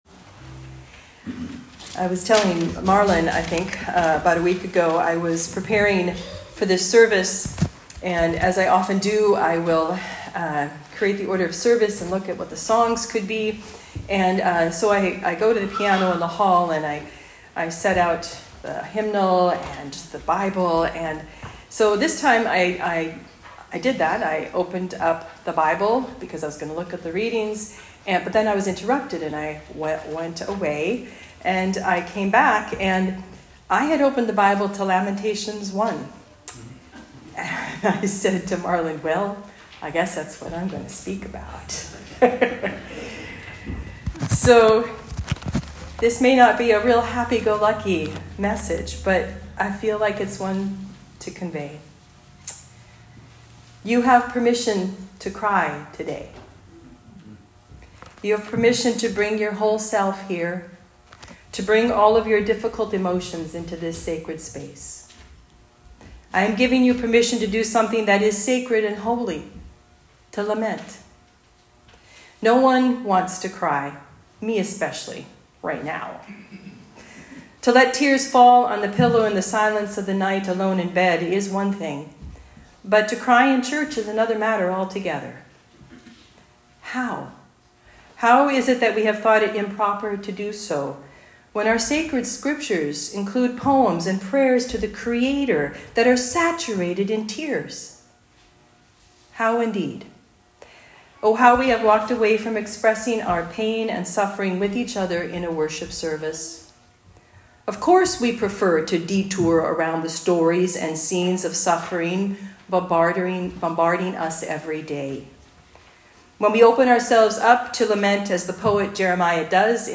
Sermons | Holy Trinity North Saanich Anglican Church